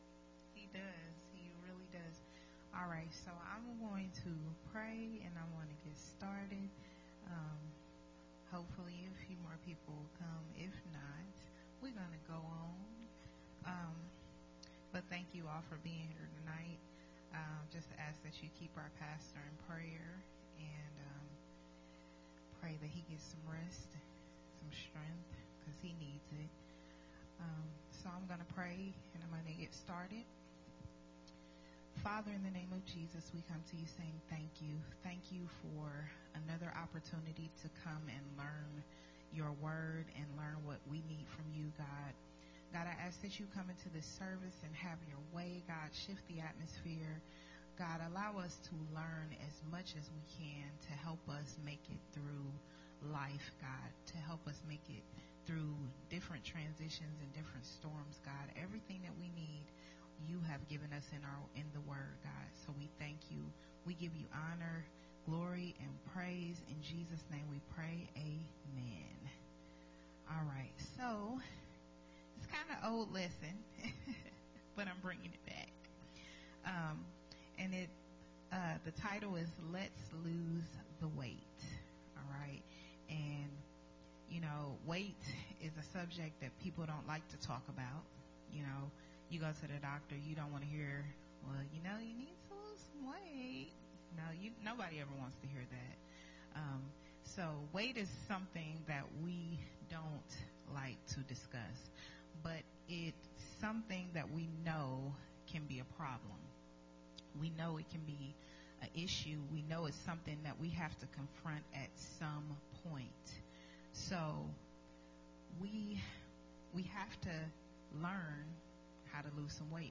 a Family Training Hour teaching
recorded at Unity Worship Center on November 3, 2021